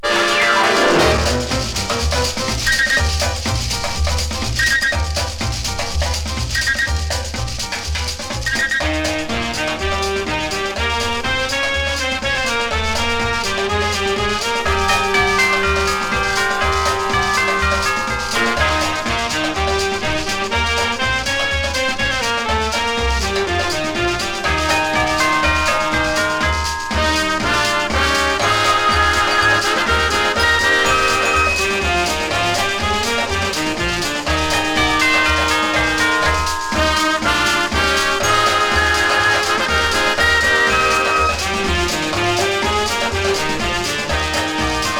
キレッキレで迫力ある音と楽曲の親しみやすさ、普遍とも感じるこの楽しさに嬉しくなります。
Latin, World　USA　12inchレコード　33rpm　Stereo